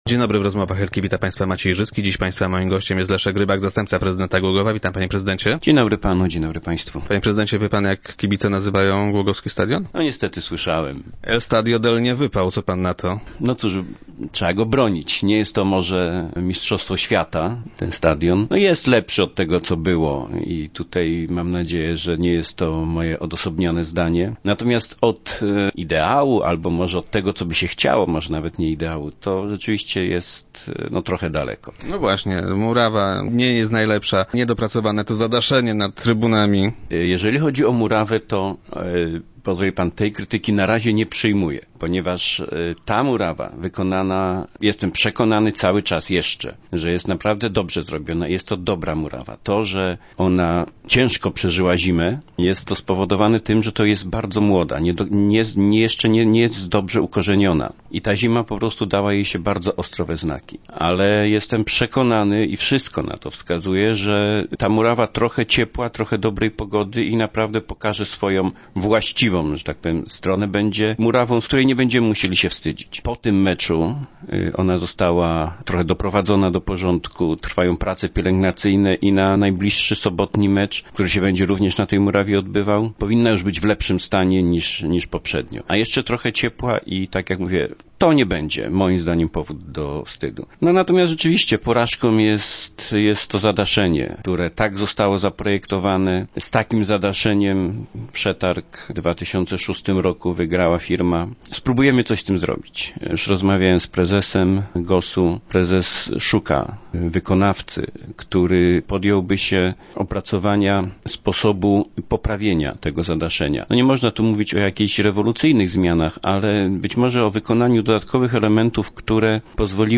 Leszek Rybak, zastępca prezydenta i gość dzisiejszych Rozmów Elki, nie ukrywa, że nie jest on wolny od wad, ale i tak jest lepszy, od stadionu sprzed remontu.